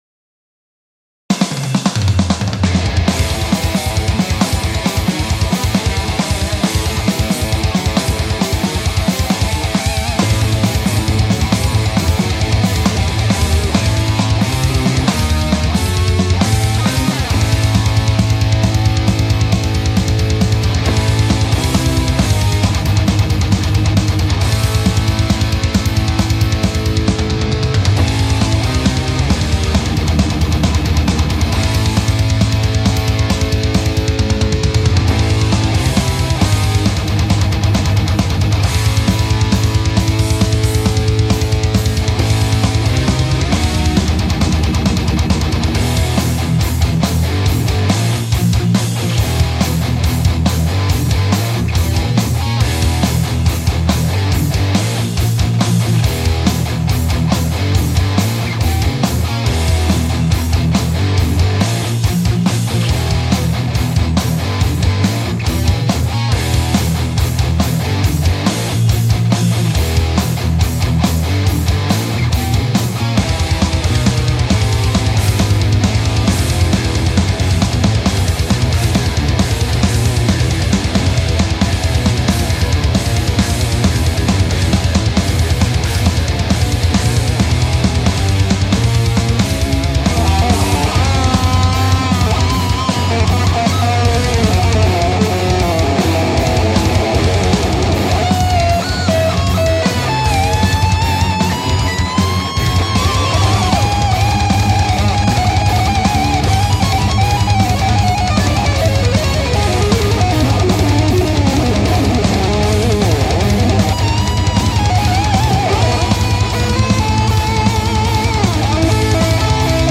Looking for some helpful feedback on drum mix
The arrangement is temporary .
I just kinda threw some solos on it . And the ending is just not done yet .